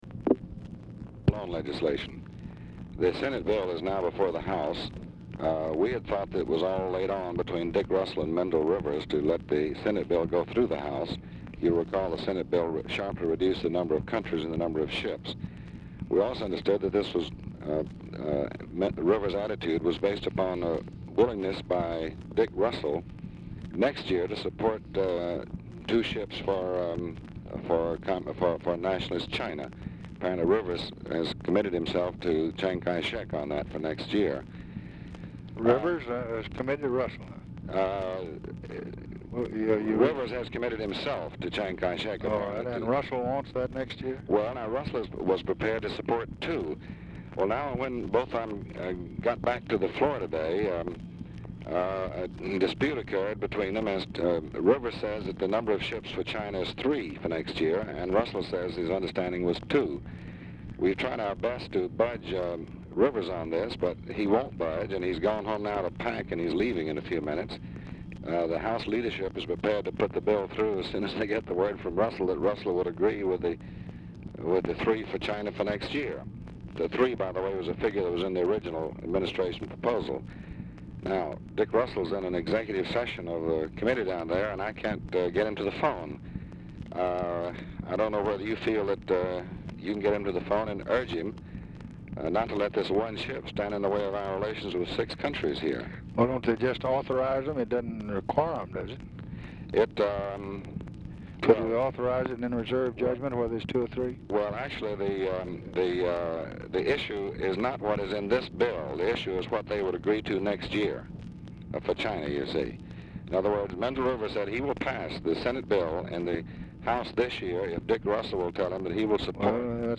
Telephone conversation # 9030, sound recording, LBJ and DEAN RUSK, 10/22/1965, 3:40PM | Discover LBJ
RECORDING STARTS AFTER CONVERSATION HAS BEGUN
Format Dictation belt
Specific Item Type Telephone conversation